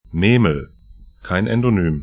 Pronunciation
Memel 'me:məl Nemunas 'nɛ:munas lt Fluss / stream 55°18'N, 21°23'E